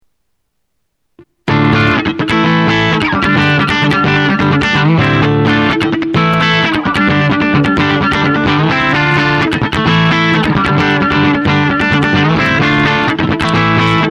これらは俺がギターでコピーしたものです。
これは俺が自分のパソコンで録音した
俺がギターで初めて練習した物です。